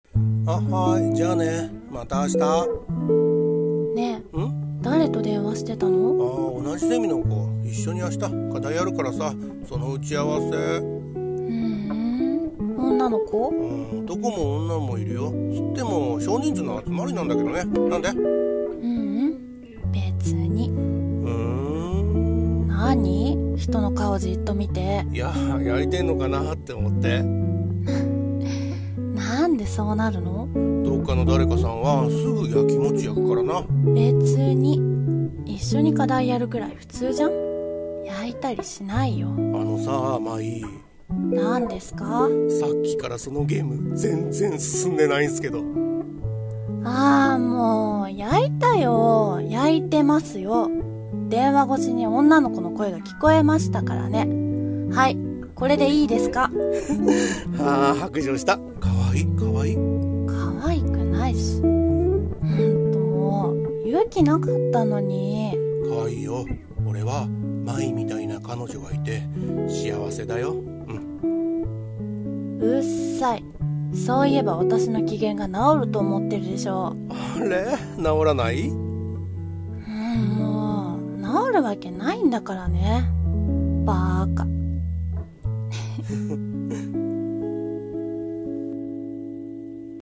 【声劇台本】素直じゃない君の【掛け合い】